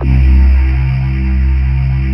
Index of /90_sSampleCDs/USB Soundscan vol.28 - Choir Acoustic & Synth [AKAI] 1CD/Partition D/17-GYRVOC 3D